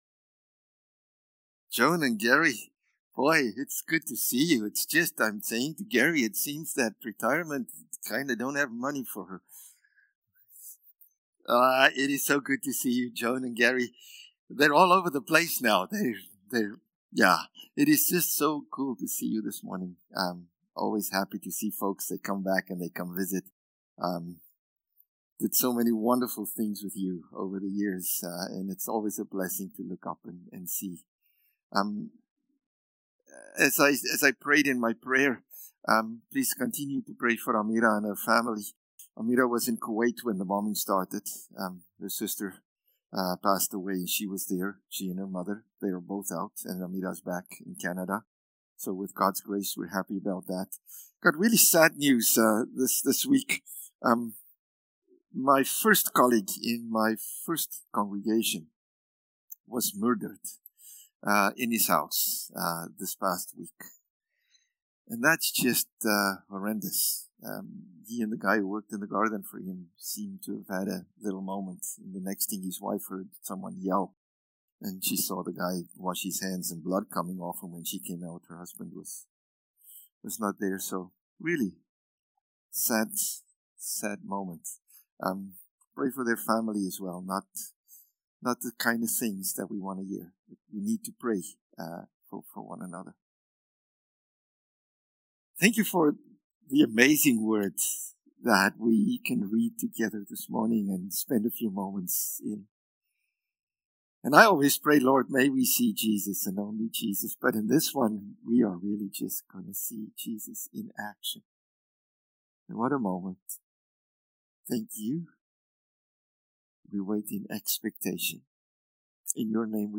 April-12-Sermon.mp3